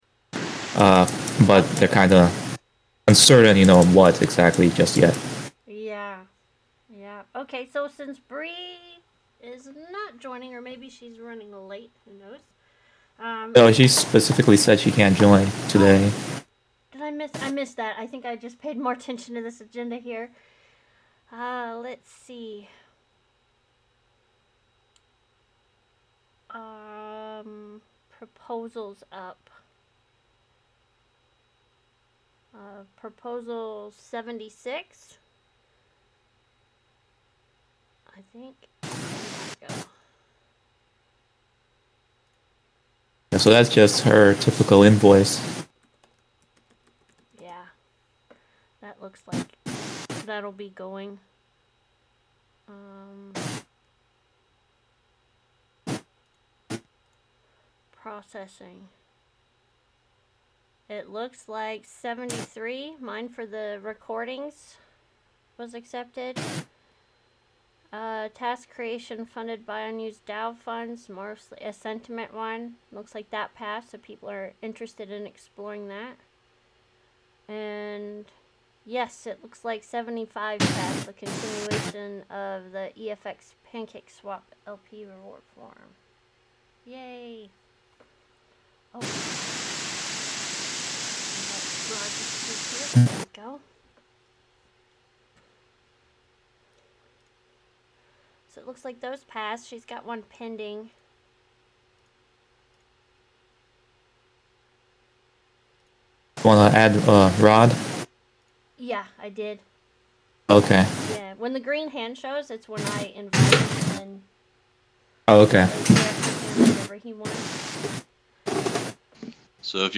DAO Call.